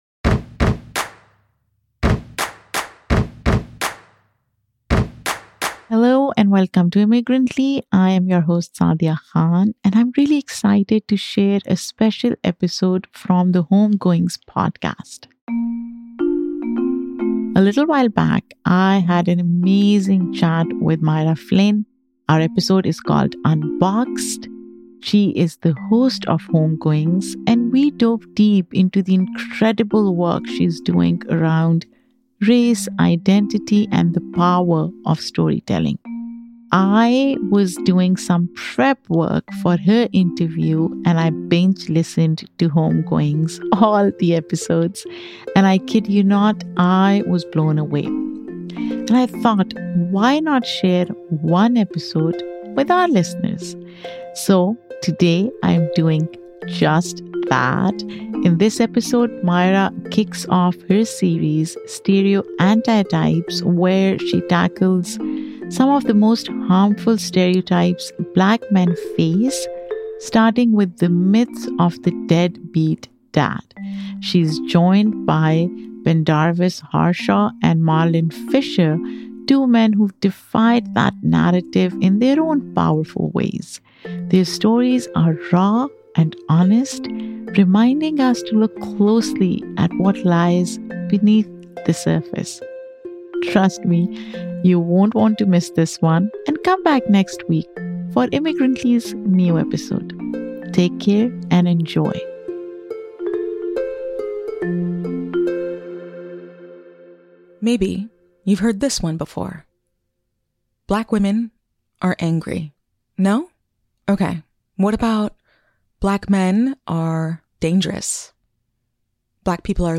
A heads up: This episode contains strong language and unbleeped swearing***